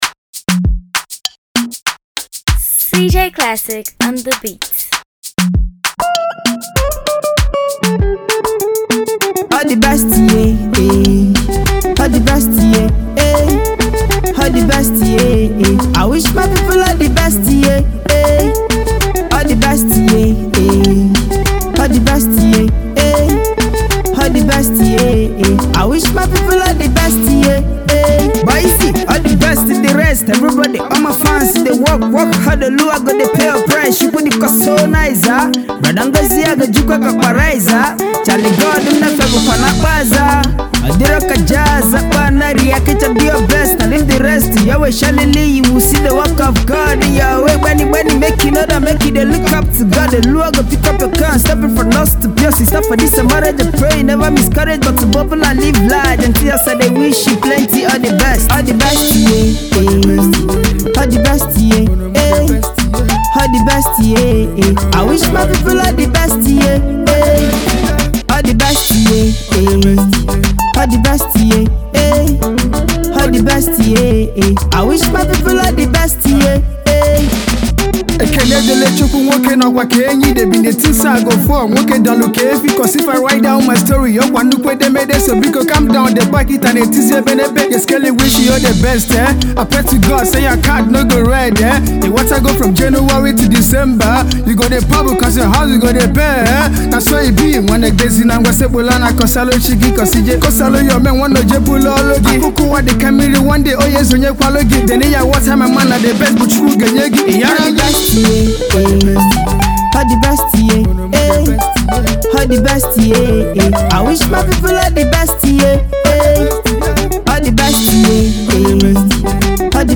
new skool rapper
lively highlife tune